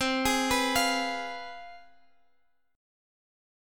Listen to CM7sus4#5 strummed